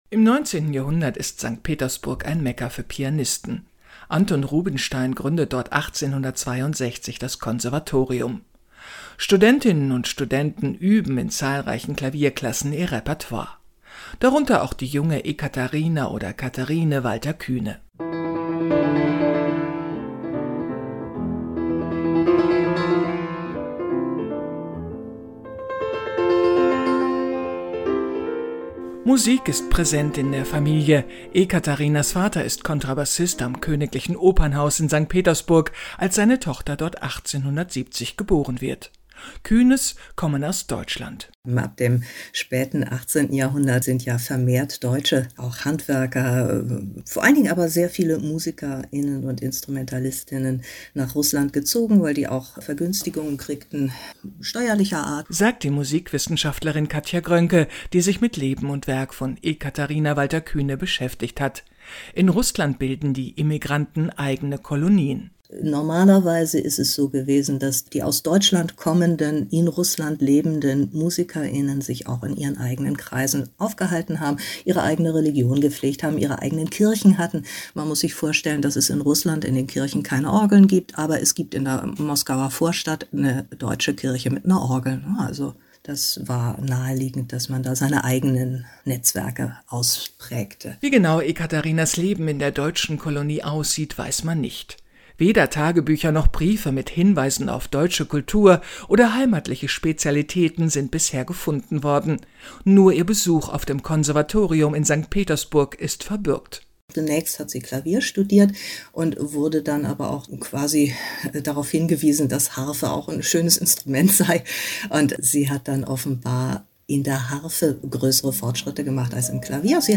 3.  Mitwirkung an Rundfunk- und Fernsehbeiträgen